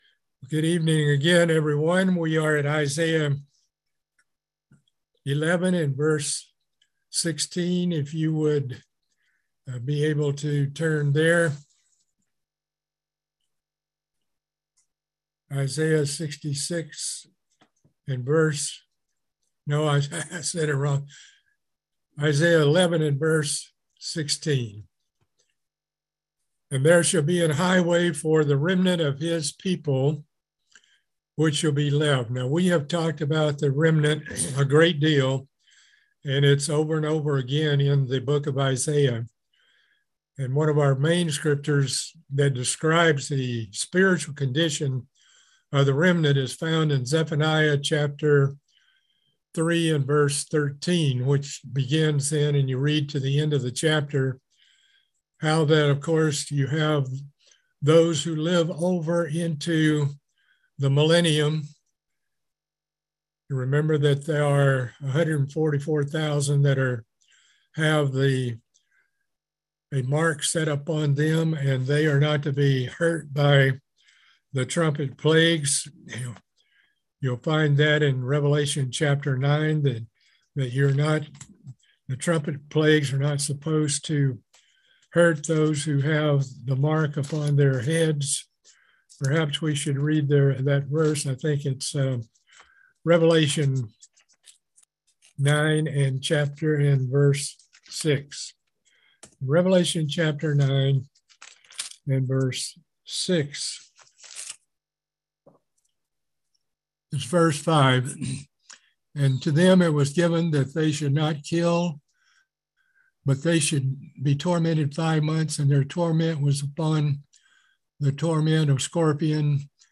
Book of Isaiah Bible Study - Part 11